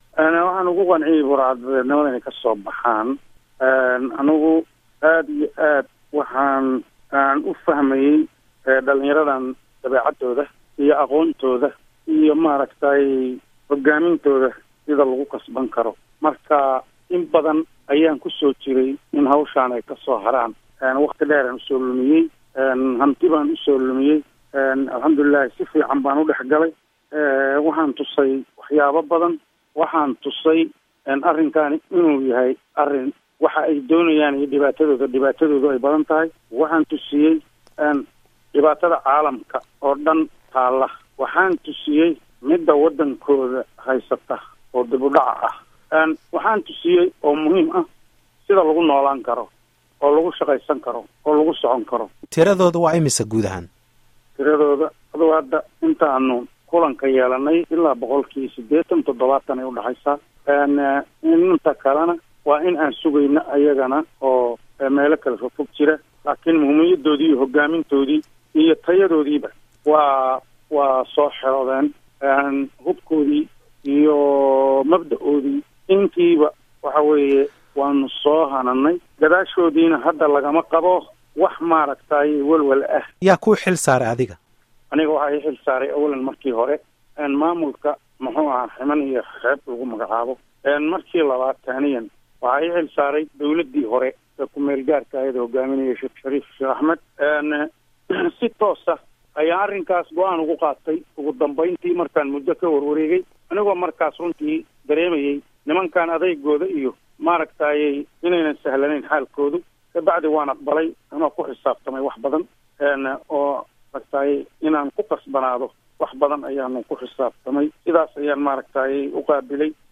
Waraysiga